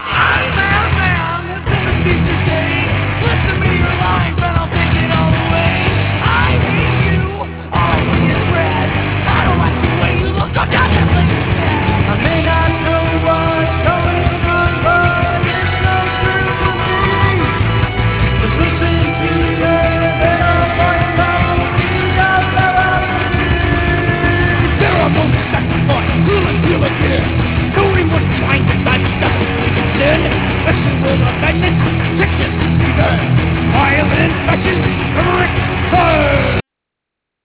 A high-enery song, I, Madman utilizes the voices of three of the band members to depict different views of the "madman".